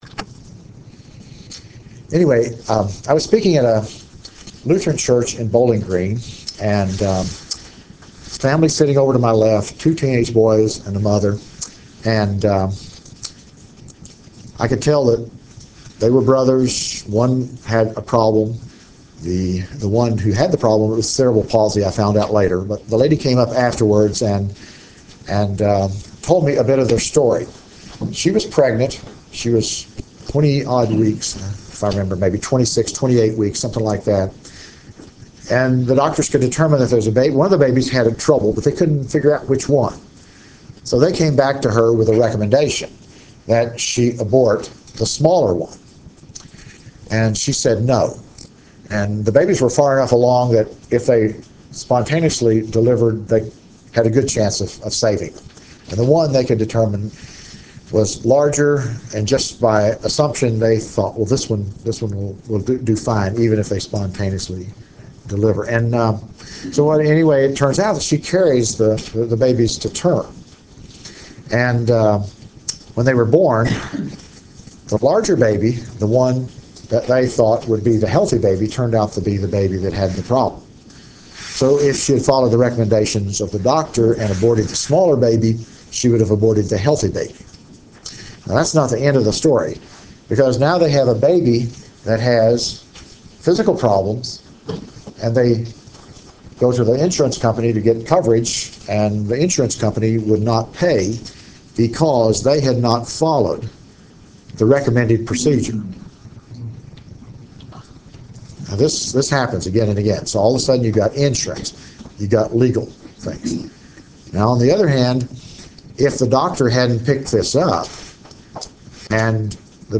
Address: Biotechnology and the Rise of Anti-Culture: Beyond Good, God and Man Recording Date